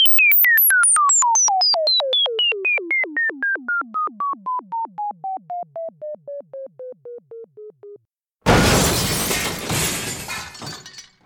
Falling_Computer_With_Crash
cartoon computers crash funny mixture sound effect free sound royalty free Funny